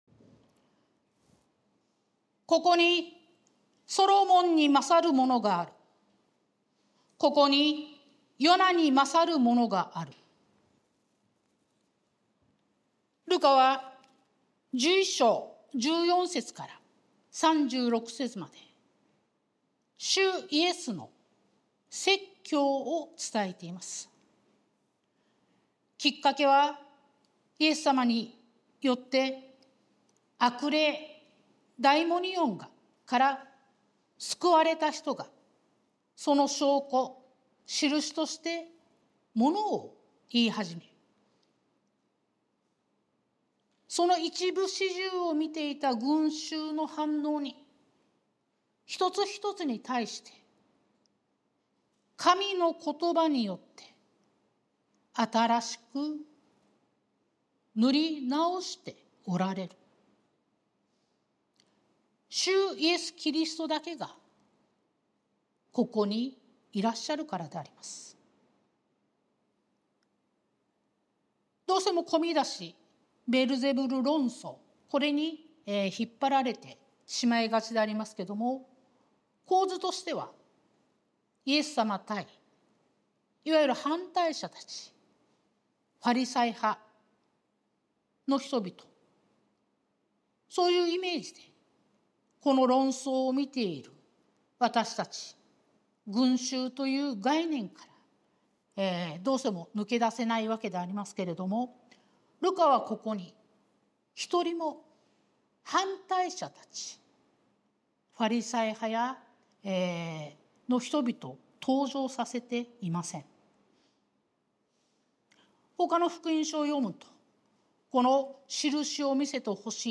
sermon-2024-07-21